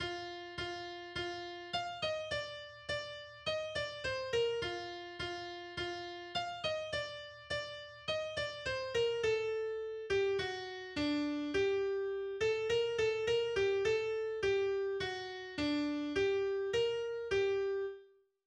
russisches Volkslied